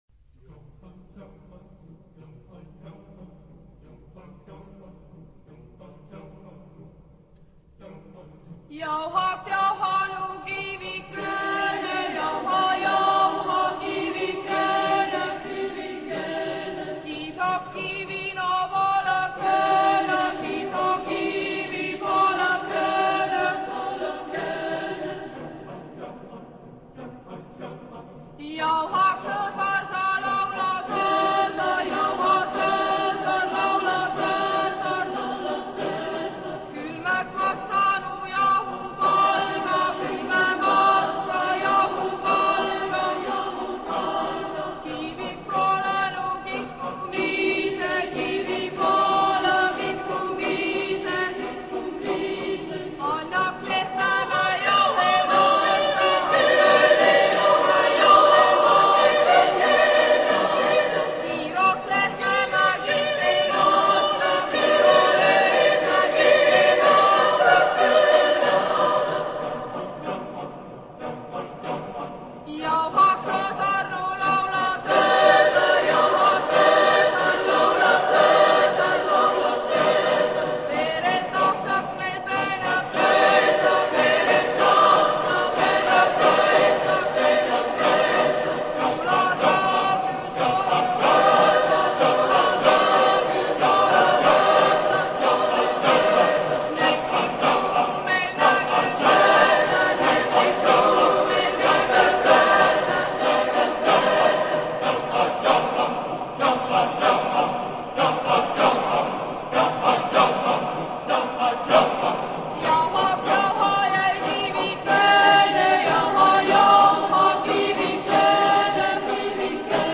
Chorgattung: SSAATB  (6-stimmiger gemischter Chor )